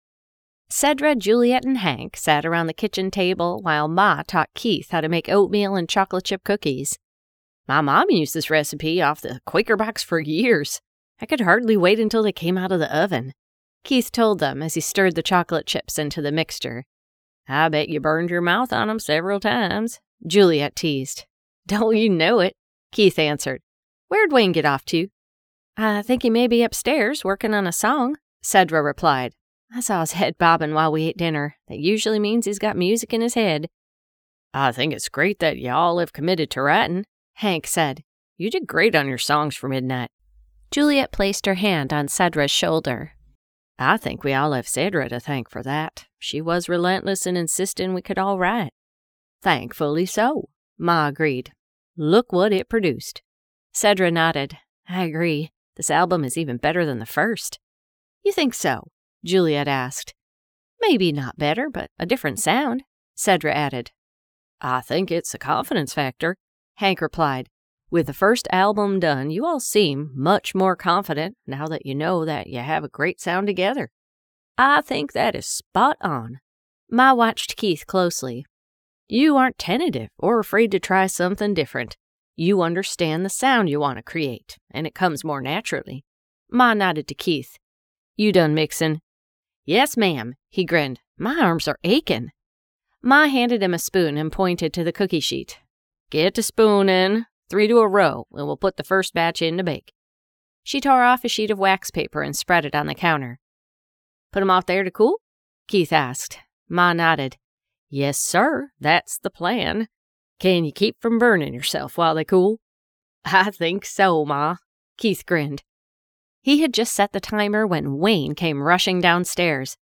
Out and Loud by Ali Spooner Song Writers Series Book 3 [Audiobook]